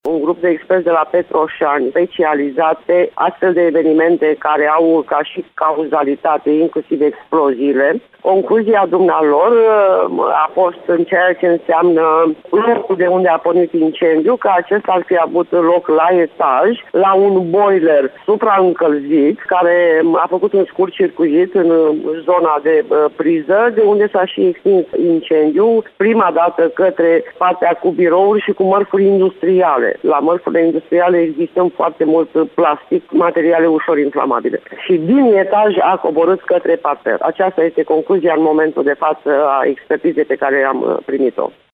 Viceprimarul Aurelia Simionică a declarat, astăzi că urmează să se constate cine a lăsat boilerul în funcţiune pe timpul nopţii.